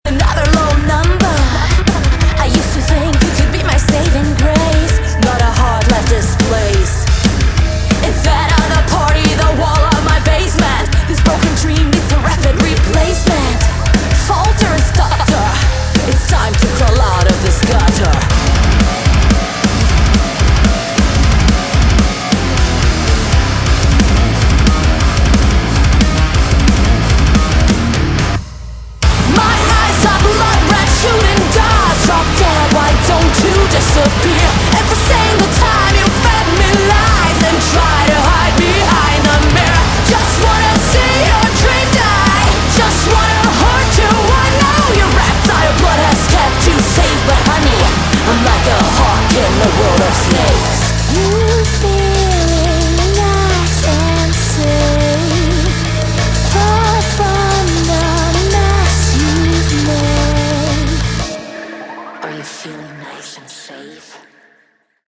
Category: Melodic Metal
vocals
guitars, bass, synths/electronic arrangements
drums